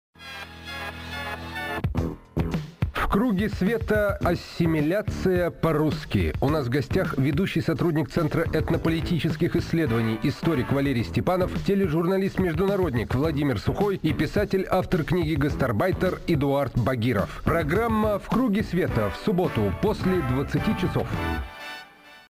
на радио «Эхо Москвы»
Аудио: анонс –